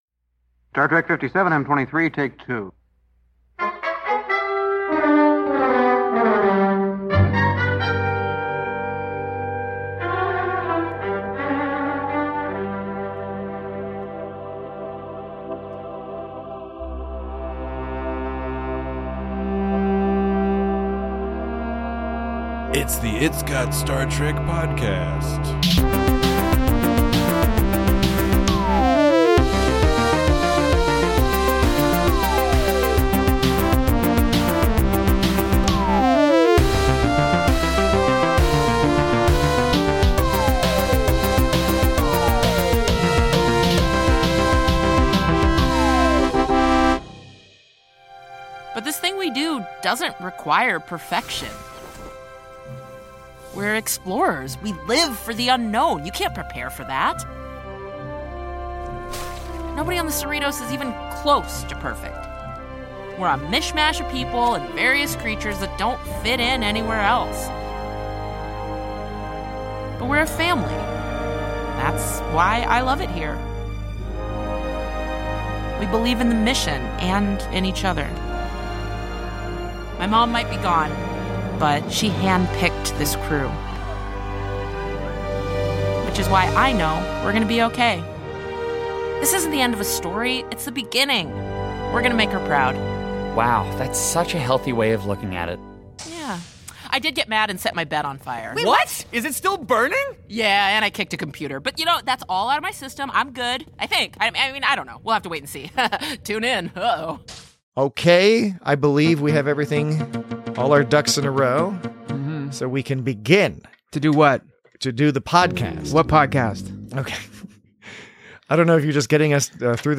Join your misty-eyed hosts as they discuss a brilliantly-conceived and produced series finale.